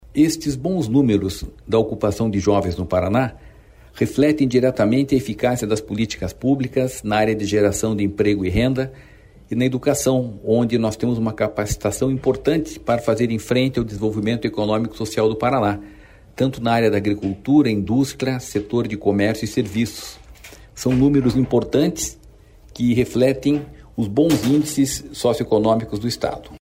Sonora do diretor-presidente do Ipardes, Jorge Callado, sobre a diminuição no número de jovens que não estudam e nem trabalham